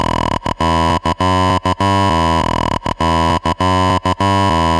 Tag: 100 bpm Electronic Loops Synth Loops 1.21 MB wav Key : C